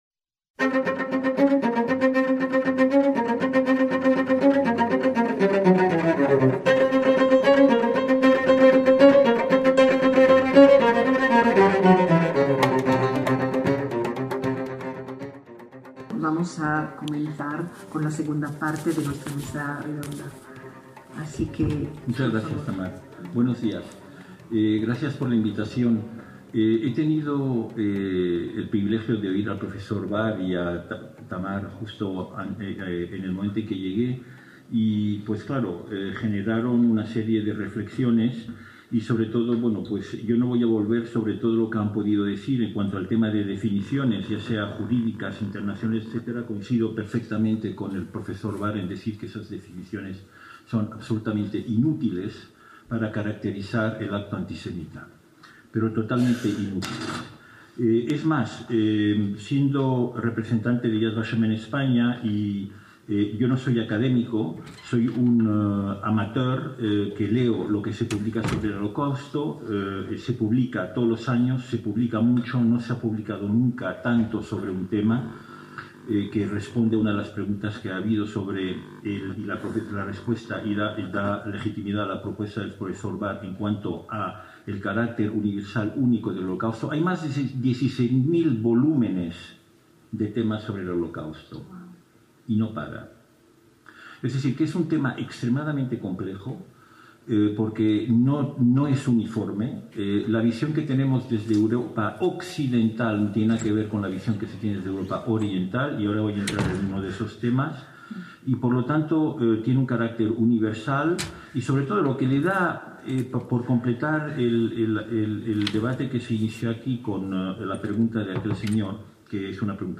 VI SEMINARIO INTERNACIONAL SOBRE ANTISEMITISMO - Los pasados 17 y 18 de noviembre de 2022, tuvo lugar en el Aula Magna de la Facultad de Educación de la Universidad Complutense de Madrid el VI Seminario Internacional sobre Antisemitismo.